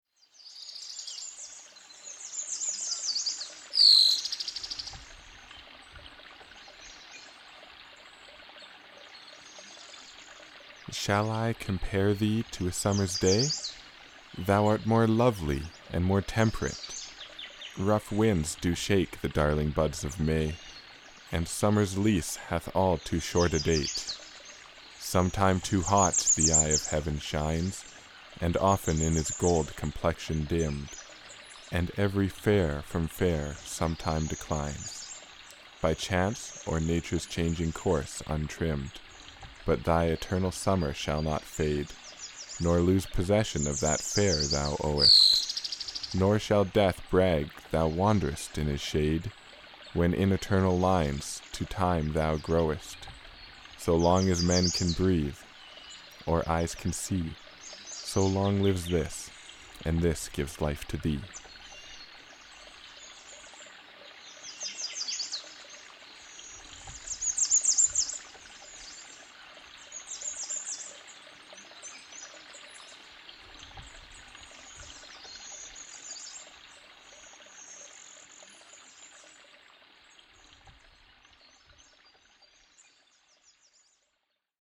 They are recorded below or above the level of hearing and are masked by the sound of the music.
wp-content/d-load/audio/shakespeare.mp3 In the second example, you will no longer hear the affirmations. My voice is inaudible, and an additional track has been added with nature sounds.
subliminal2.mp3